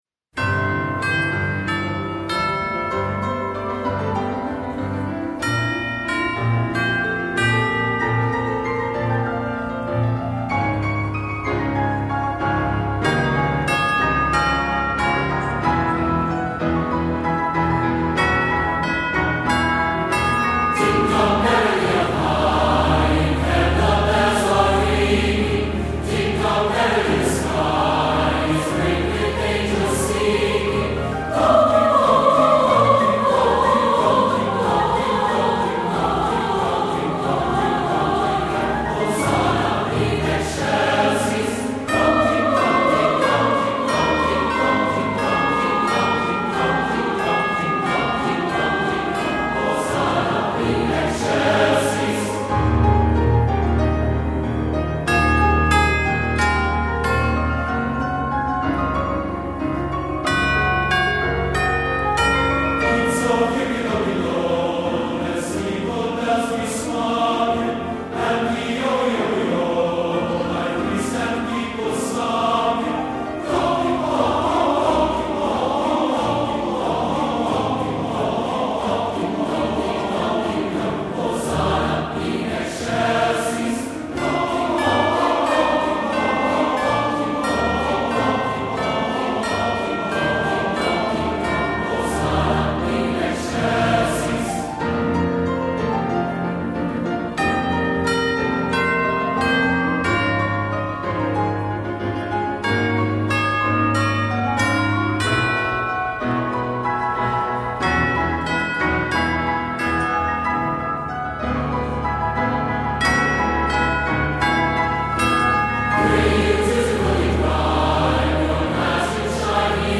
Accompaniment:      Piano, Four-hand piano;chimes
Music Category:      Choral